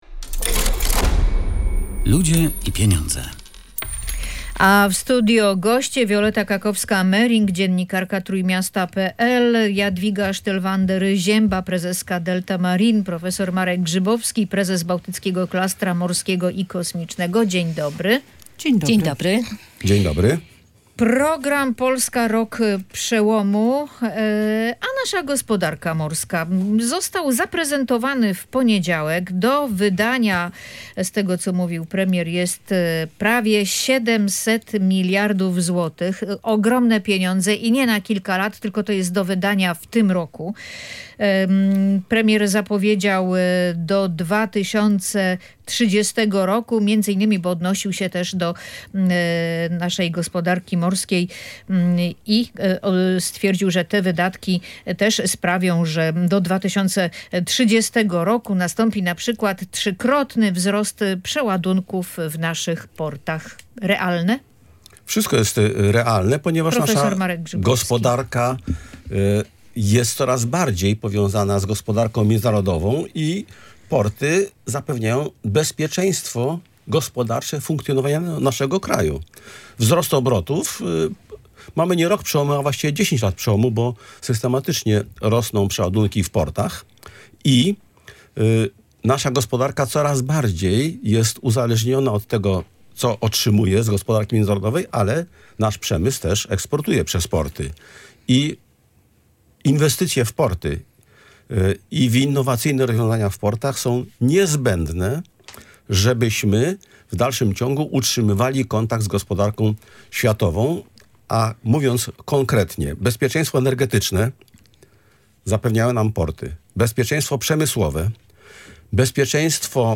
Między innymi o tym rozmawiali goście audycji